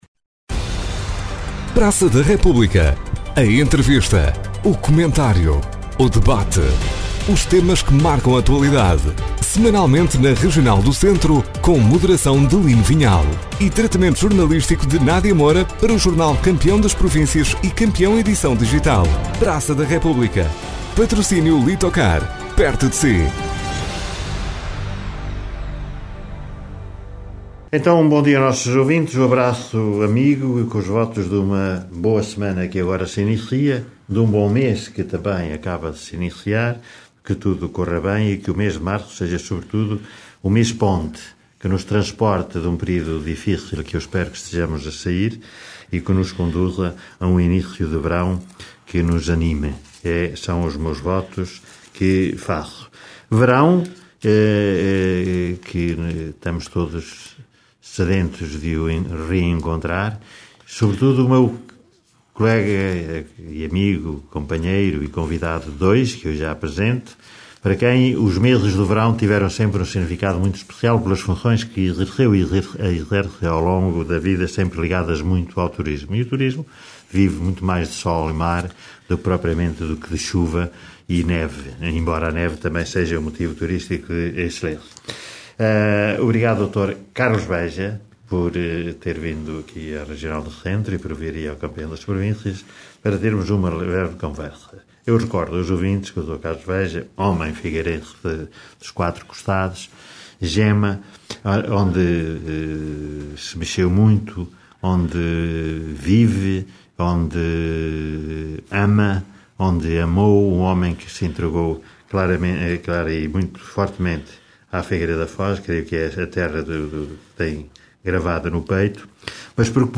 Praça da República – Entrevista